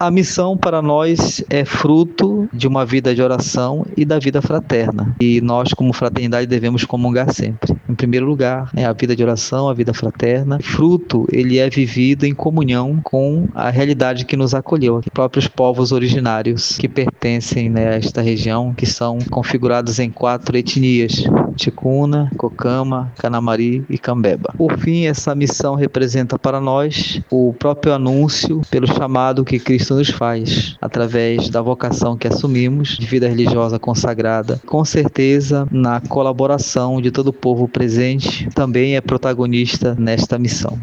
O entrevistado é